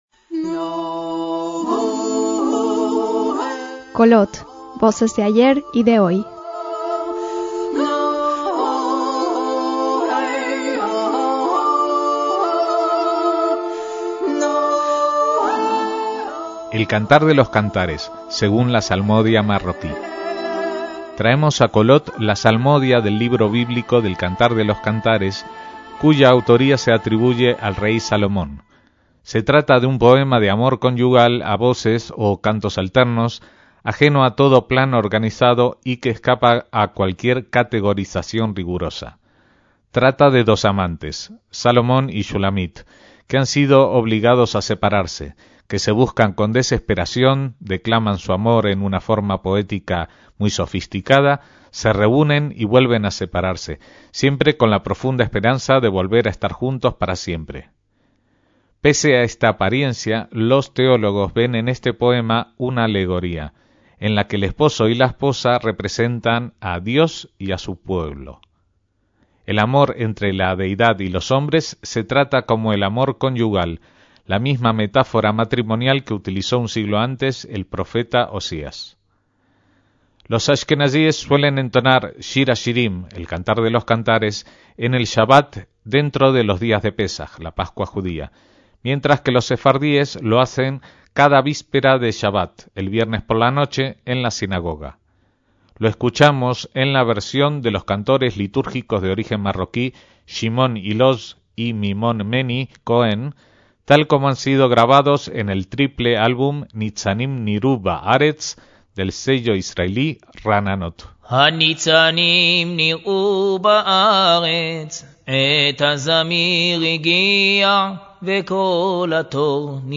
El Cantar de los Cantares, según la salmodia marroquí